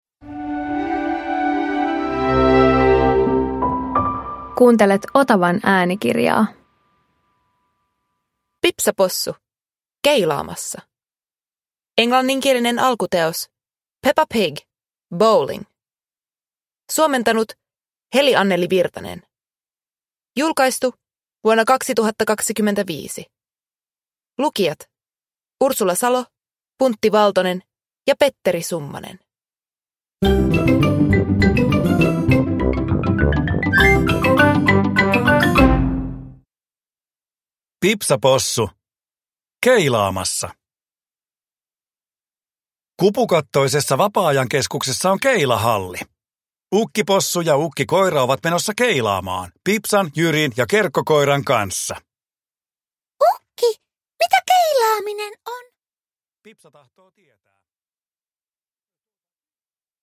Pipsa Possu - Keilaamassa – Ljudbok